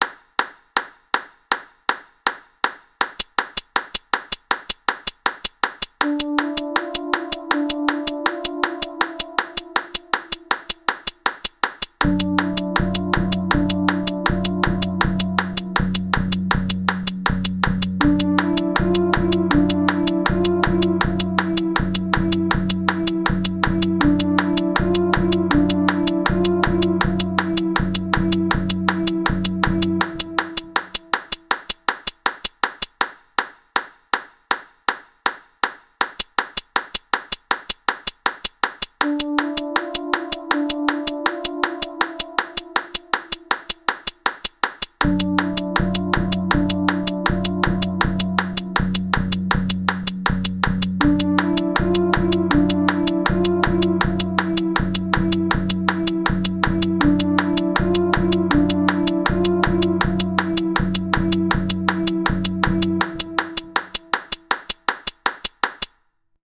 Game Music
eerie spooky